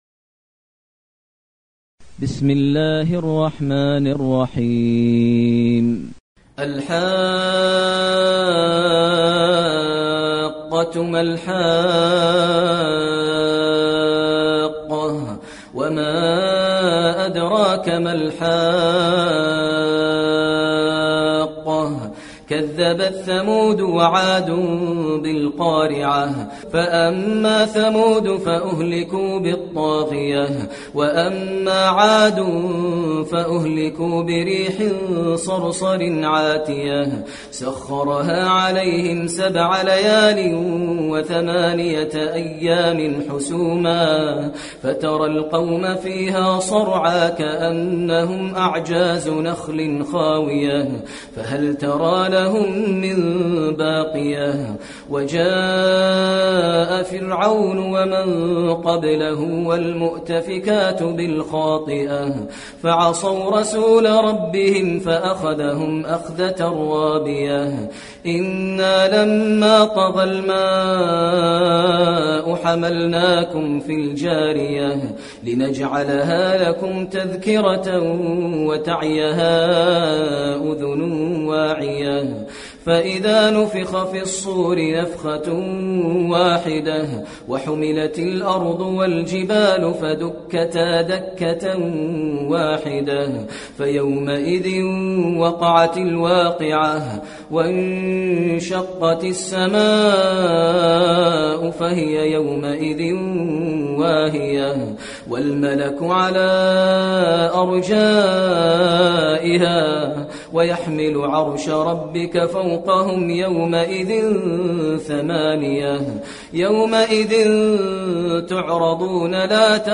المكان: المسجد النبوي الحاقة